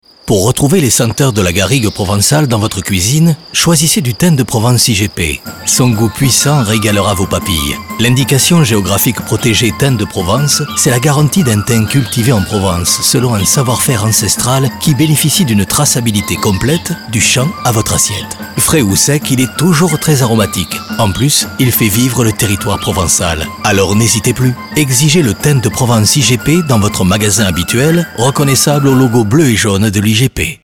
AIHP_IGP-Thym-de-Provence_spot_Radio_FBP_2019.mp3